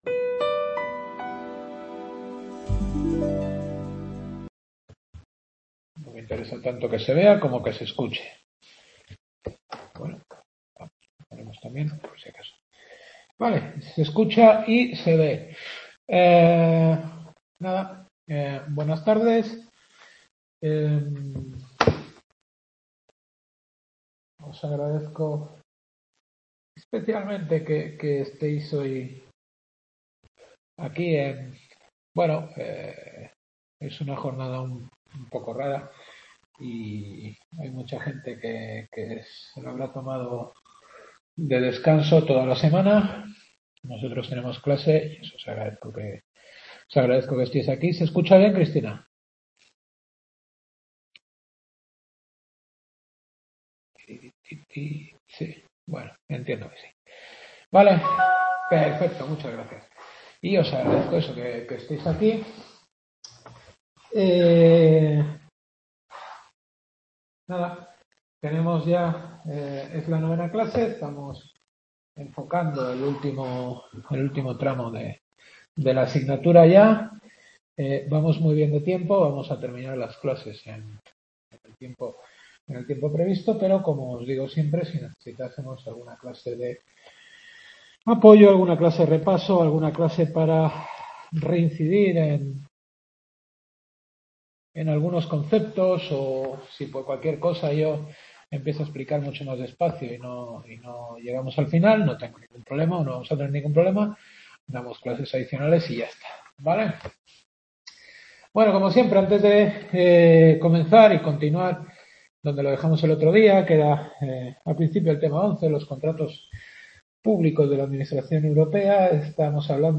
Novena clase.